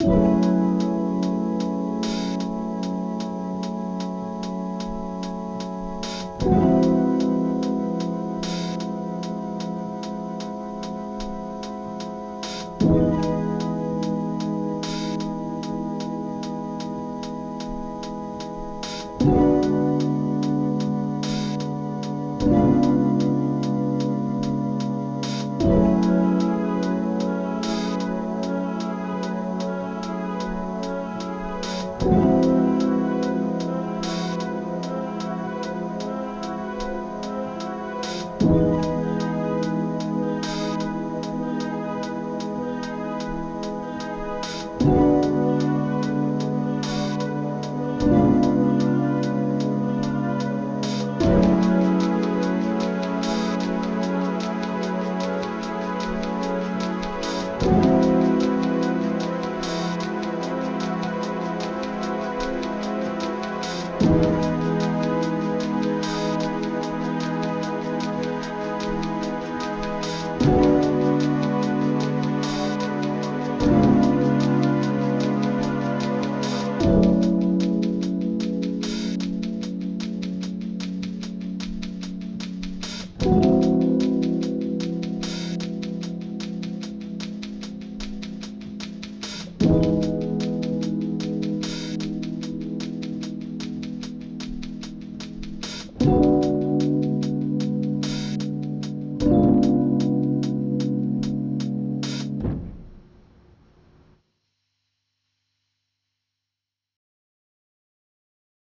Lo-Fi Wicker Intensity 2.wav